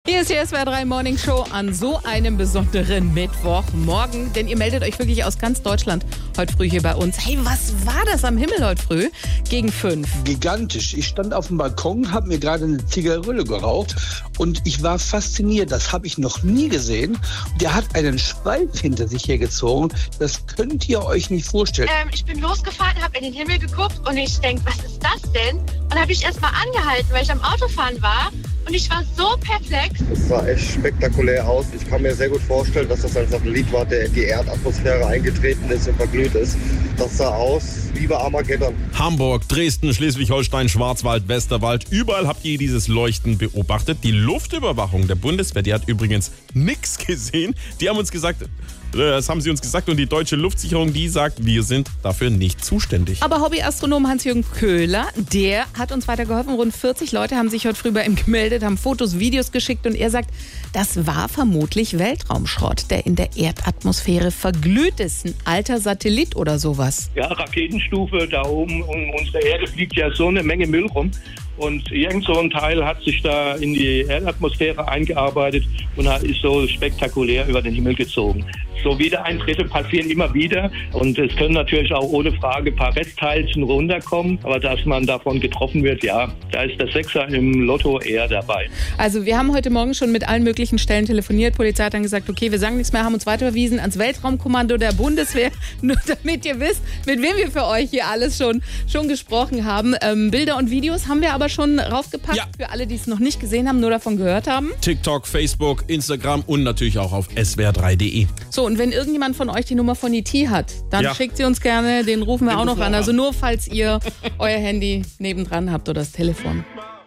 Nachrichten SWR3 Morningshow: Was waren das für Lichtschweife am Nachthimmel?